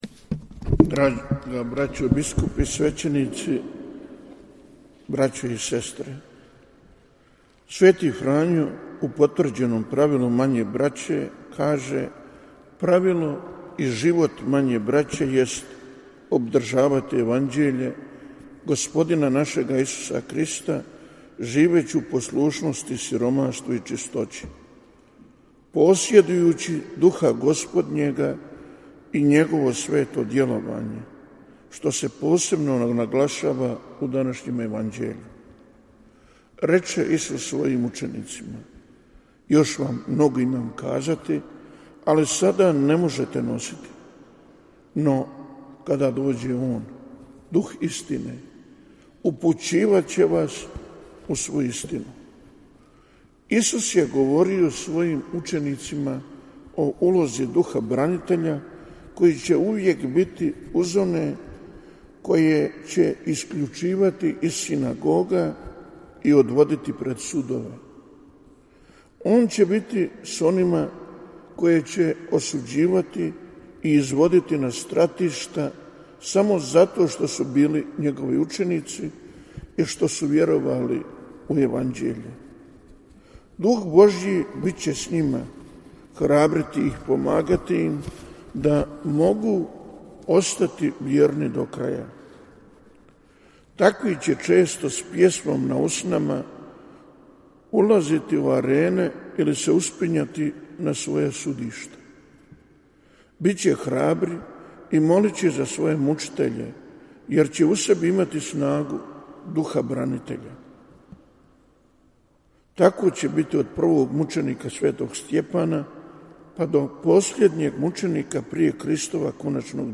AudioIzdvajamoPropovijedi
Audio: Propovijed biskupa Marka Semrena na Svetoj misi biskupa Biskupske konferencije BiH u Bazilici sv. Ivana Lateranskog